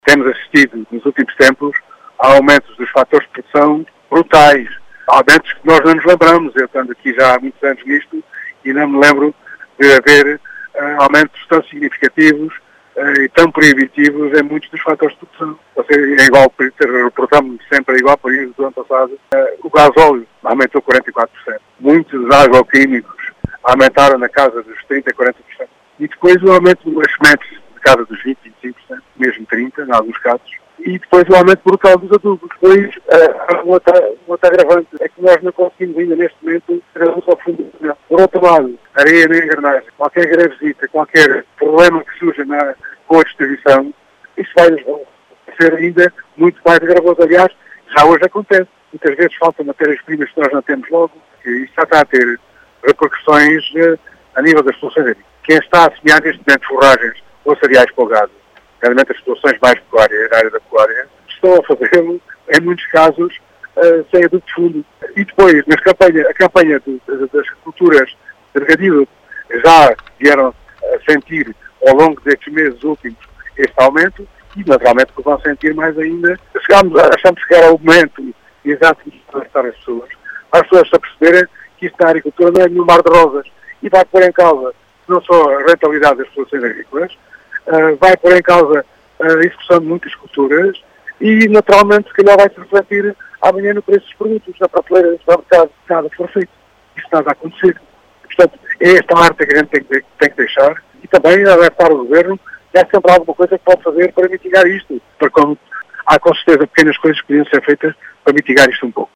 Em declarações à Rádio Vidigueira